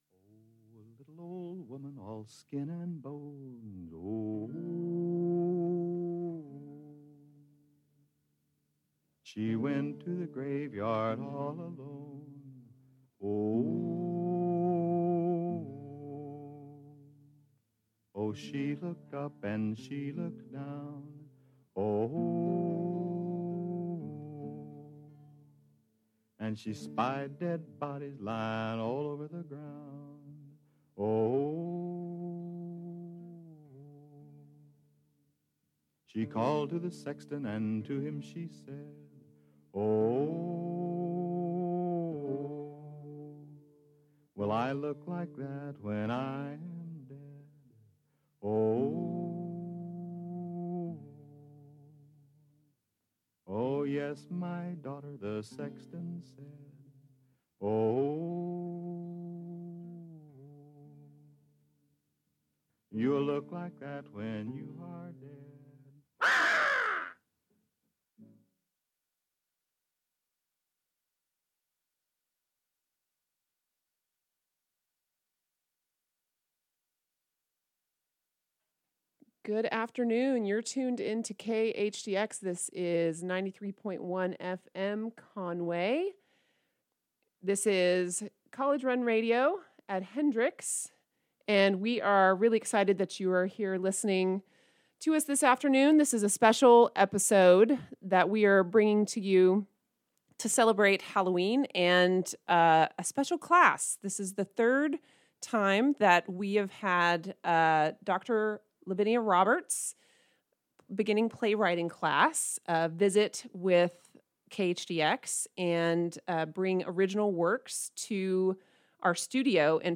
Listen to the audio above for a variety of performances by Hendrix students and brief interviews with the playwrights.